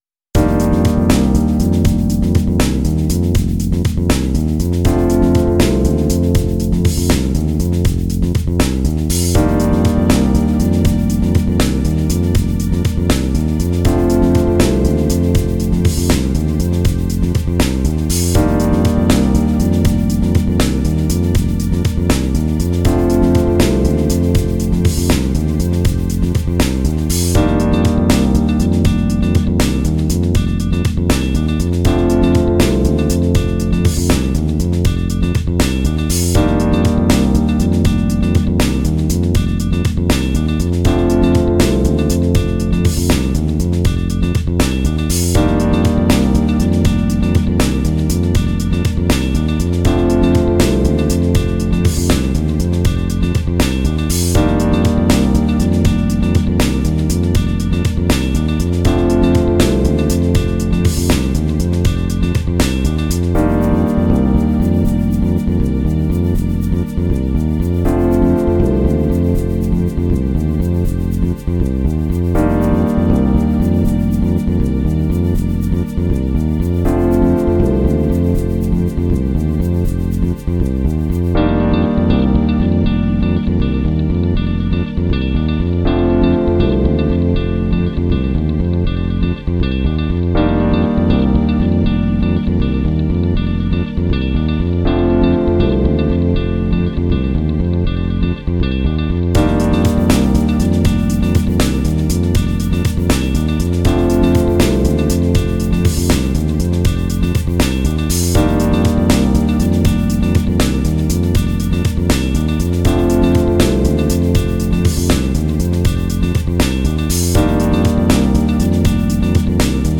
это мощная композиция в жанре поп-рок